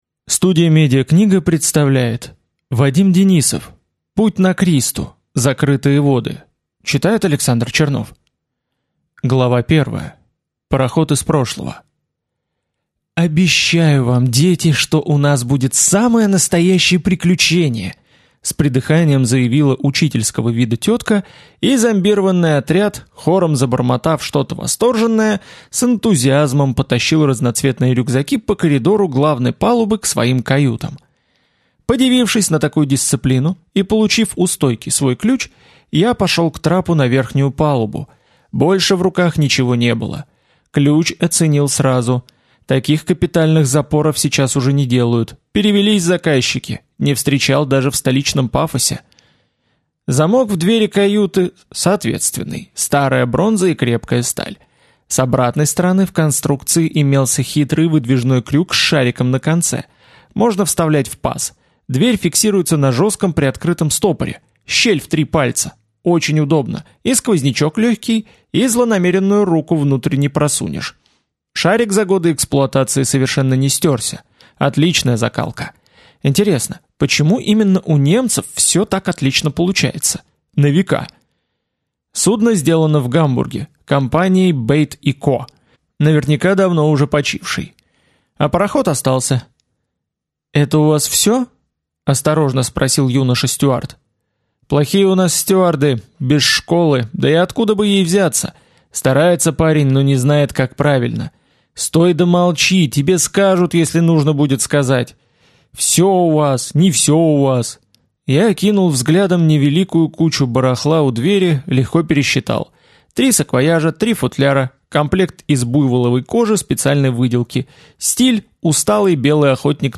Аудиокнига Путь на Кристу. Закрытые воды | Библиотека аудиокниг